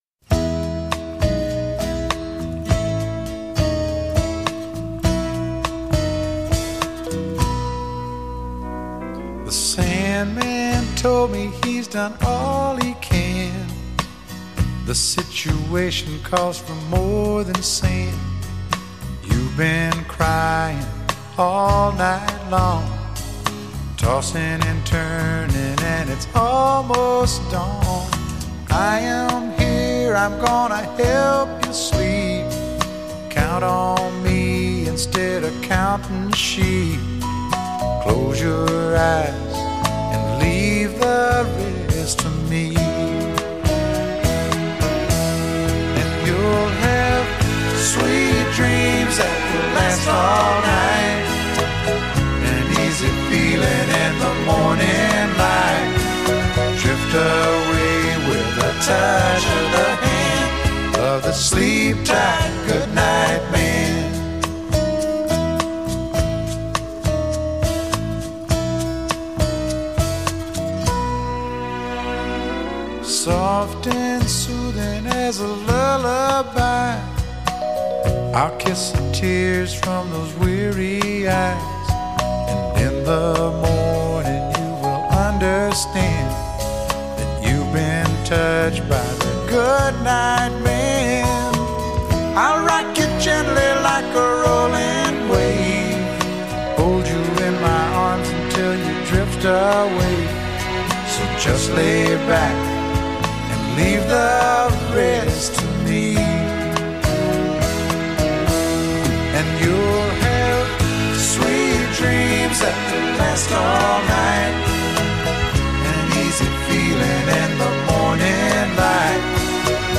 The album mixed country and pop sounds